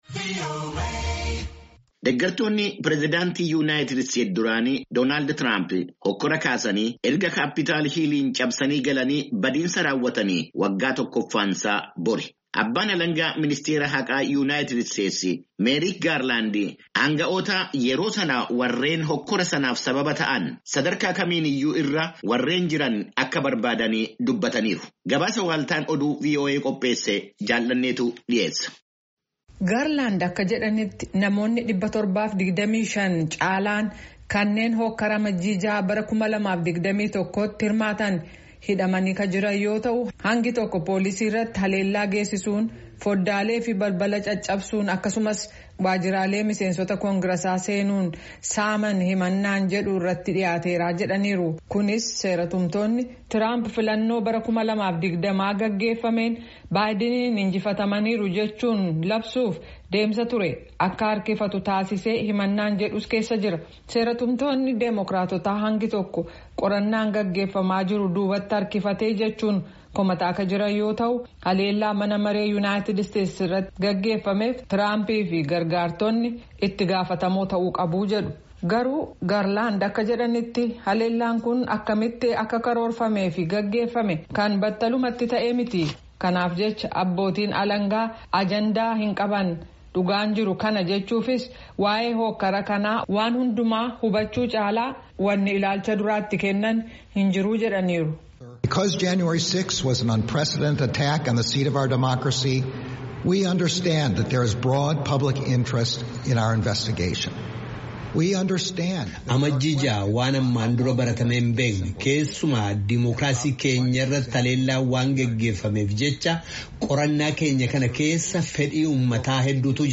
Yeroo Amajji 6, waggaa tokkoffaan deggertoonni prezidaantii duraanii Doonaald Traamp Waajira Mana-maree Yunaayitid Isteetis “Capitol Hill” jedhamu cabsanii itti seenuun miidhaa itti geessisan har’a yaadamaa jirutti, Abbaan-alangaa muummichi ka biyyattii – Meerik Gaarlaand, abbootiin alangaa ministirii murtii haqaa Yunaayitid Isteetis aangawoota sadarkaa fedheeyyuu ta’an – kanneen hookkara bara dabreef itti gaafatamoo tahan faana dhahanii ka seeratti dhiheessan tahuu dubbatan, gabaasaa guutuu caqasaa.